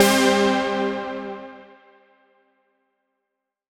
Index of /musicradar/future-rave-samples/Poly Chord Hits/Straight
FR_SARP[hit]-C.wav